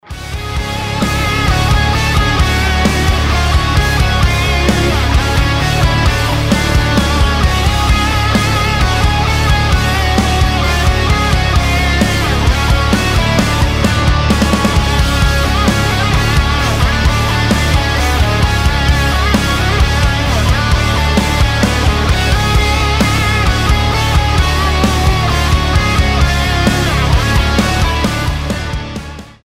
стоит признать, это неимоверно круто звучит